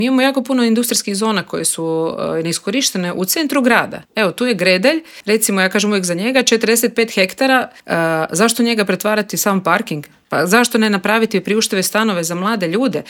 Upravo o toj temi razgovarali smo u Intervjuu Media servisa s HDZ-ovom europarlamentarkom Nikolinom Brnjac.